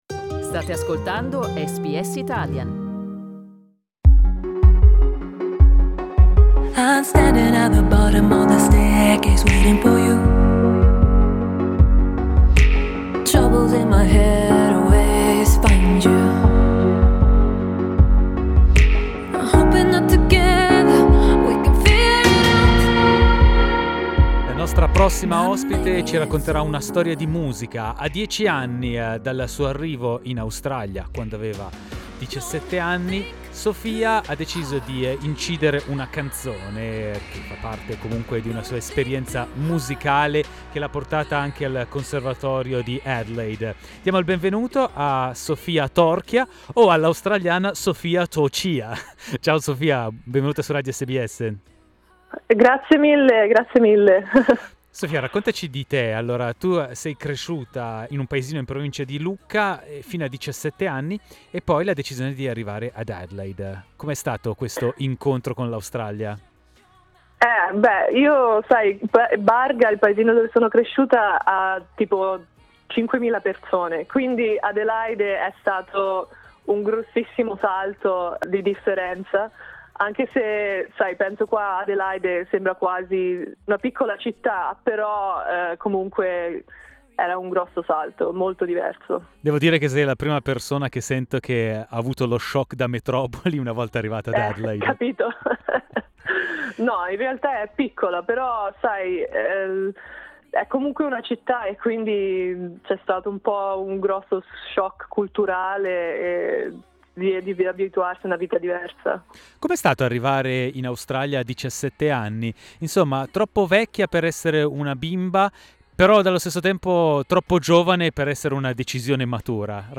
Ascolta la sua intervista a SBS Italian.